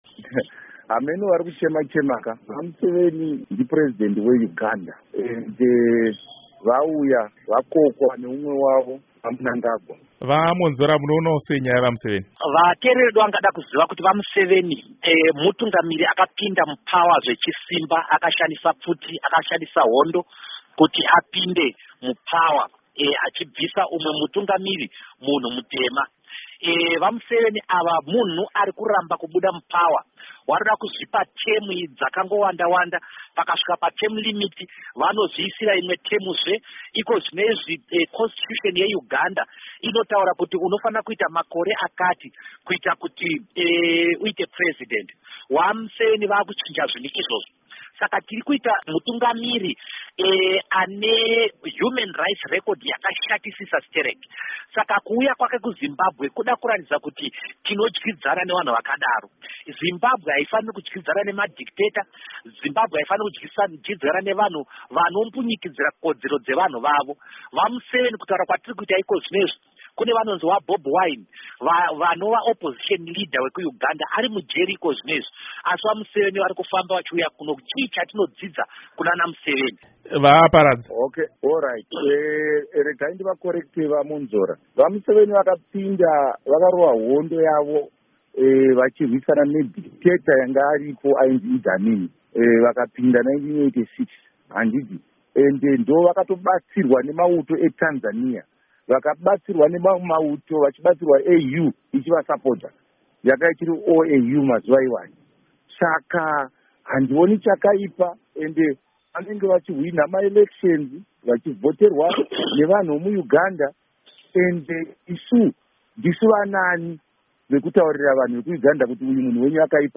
Hurukuro naVaDouglas Mwonzora naVaKindness Paradza